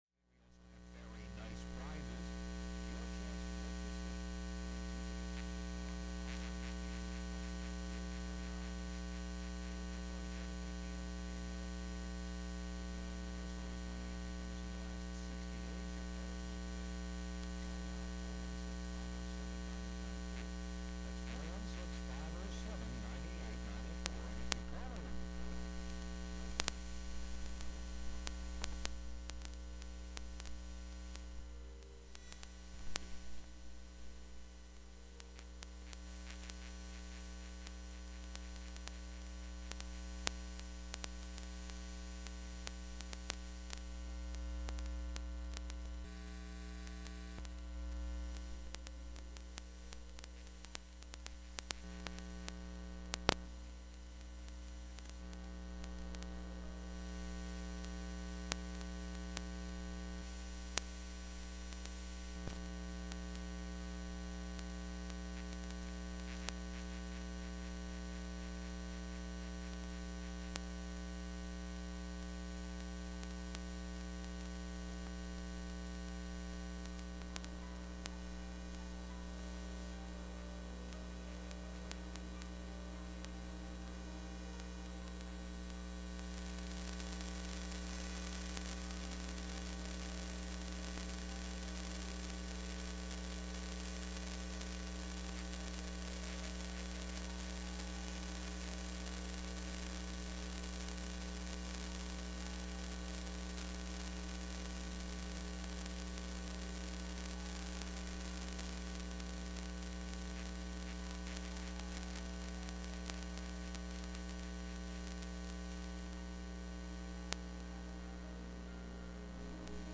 Studer A810 Tape Recorder 1982 EM Recording
This is a recording using my DIY electromagnetic microphone while running a Studer A810 Tape Recorder build in 1982. I recorded this during a part-time job at Number 9 Studio in Toronto back in 2016.